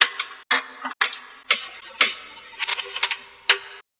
Abstract Rhythm 35.wav